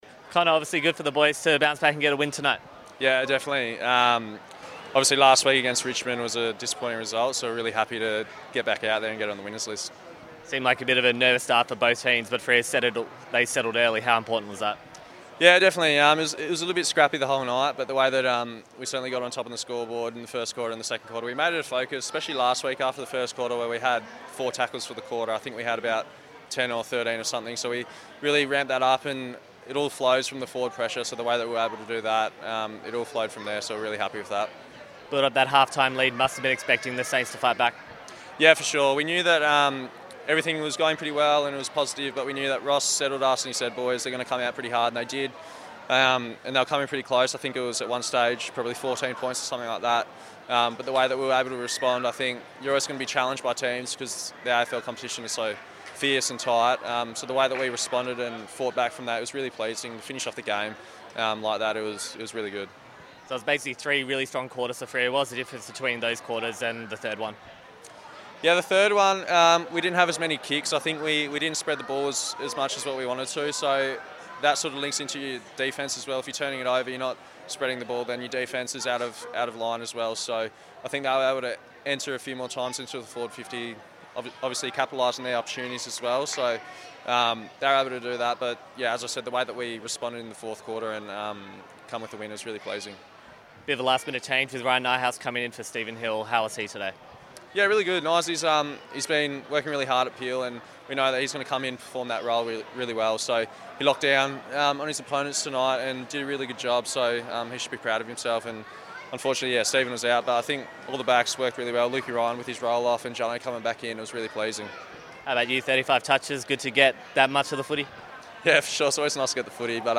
Connor Blakely post-match interview - Round 8 v St Kilda